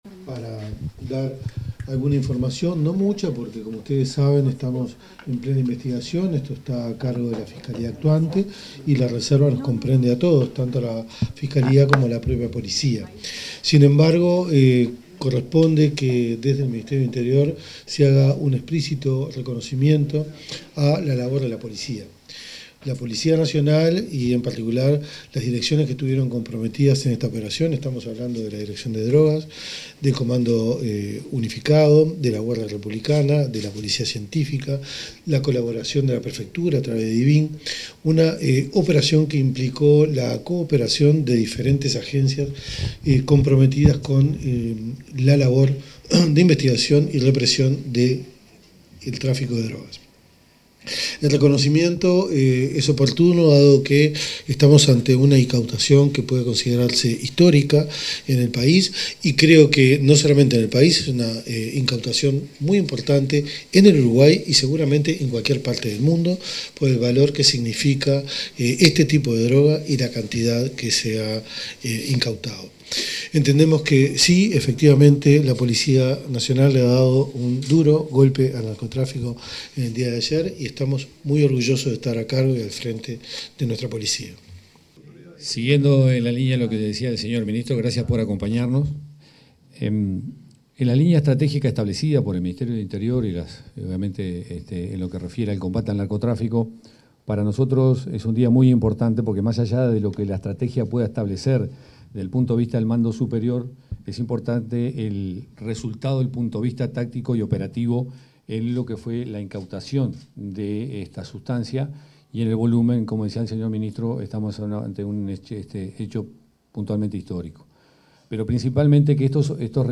Palabras de autoridades del Ministerio del Interior
El ministro del Interior, Carlos Negro, y el director nacional de Policía, José Azambuya, efectuaron declaraciones acerca del cargamento de cocaína